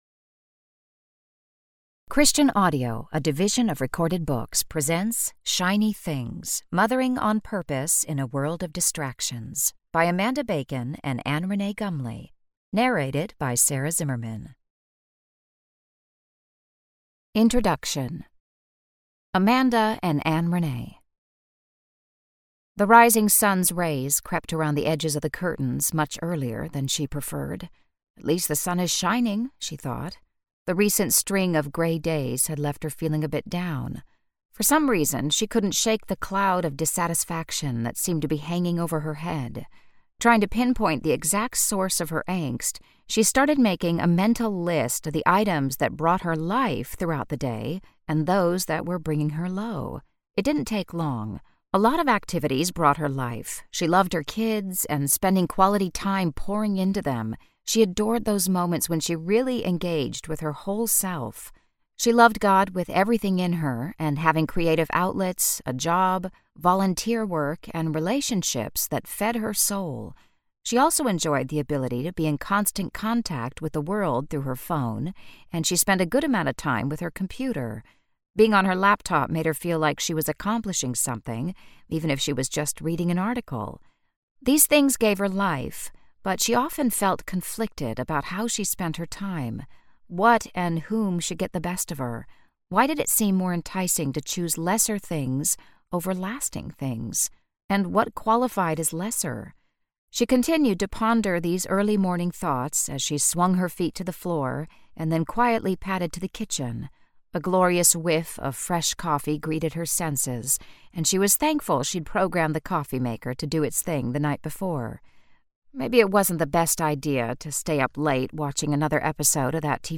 Shiny Things: Mothering on Purpose in a World of Distractions Audiobook
5.78 Hrs. – Unabridged